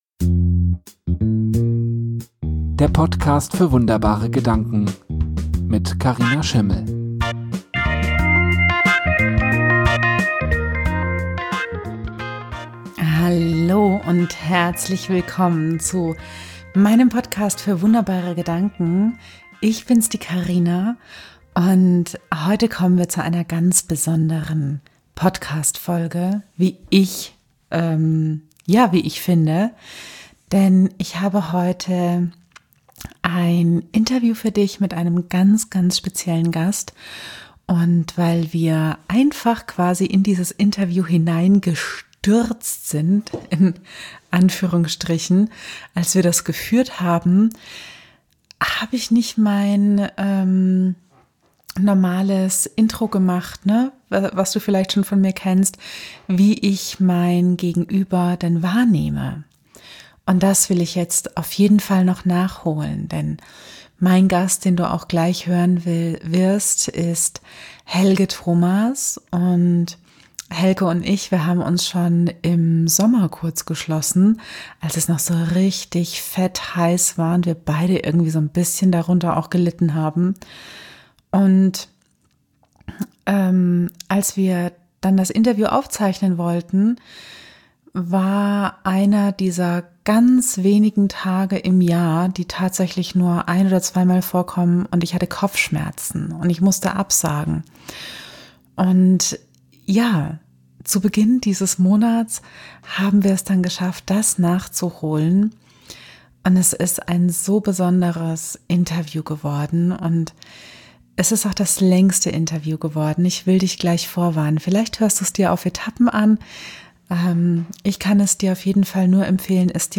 unser Interview